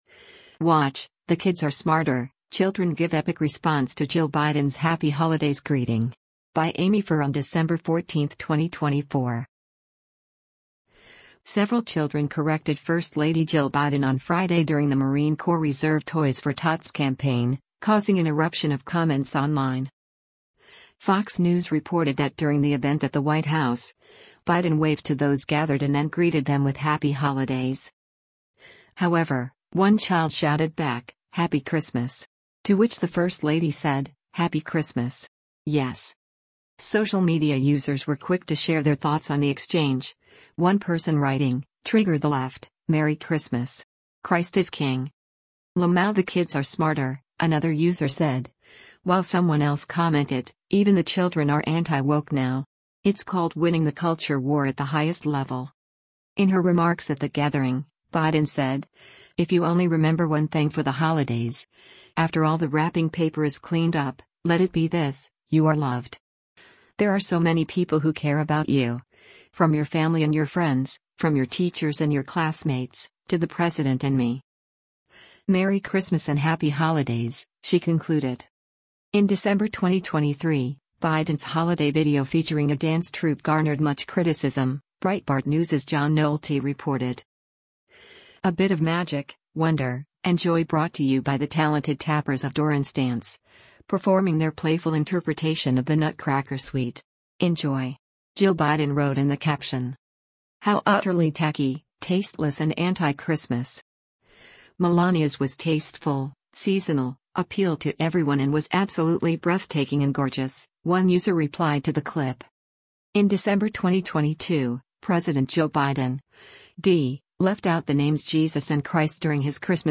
Several children corrected First Lady Jill Biden on Friday during the Marine Corps Reserve Toys for Tots campaign, causing an eruption of comments online.
Fox News reported that during the event at the White House, Biden waved to those gathered and then greeted them with “Happy holidays!”
However, one child shouted back, “Happy Christmas!” to which the First Lady said, “Happy Christmas! Yes!”: